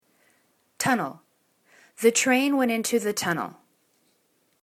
tun.nel    /'tunl/    n